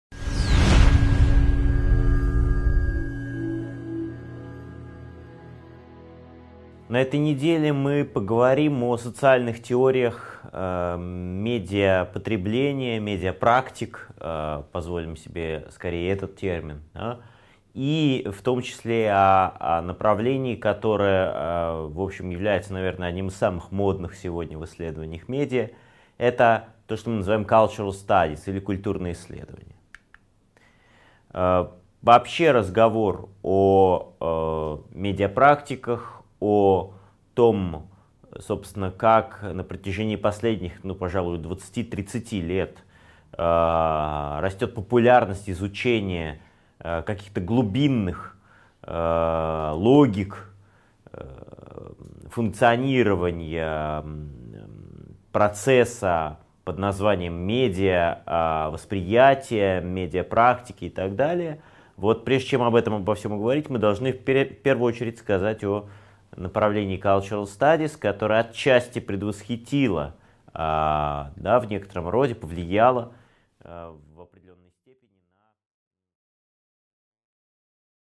Аудиокнига 11.1. Теории Cultural Studies и изучение медиапрактик: Зарождение Cultural Studies | Библиотека аудиокниг